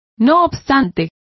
Complete with pronunciation of the translation of yet.